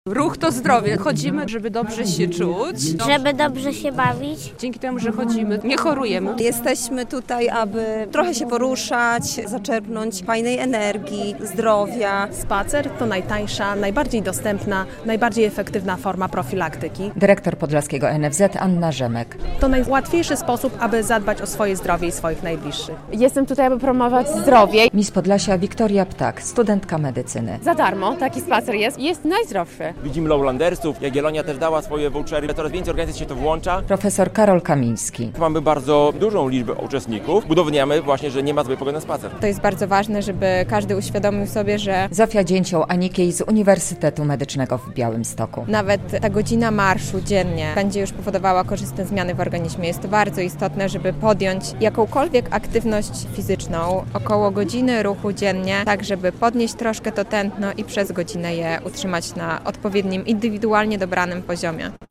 Na dziedzińcu Pałacu Branickich zebrało się w niedzielę (26.10) ponad 300 osób, by wspólnie wyruszyć w trasę po mieście.
relacja